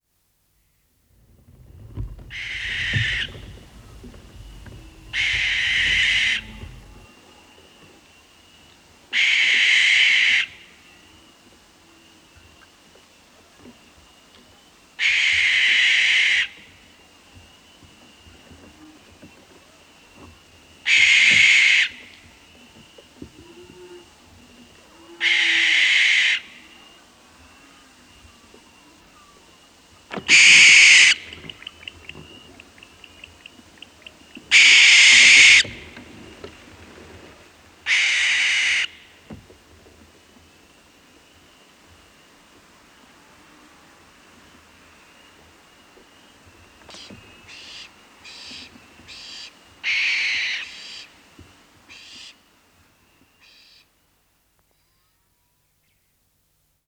Schleiereule Ruf
Schleiereule-Geraeusche-Voegel-in-Europa.wav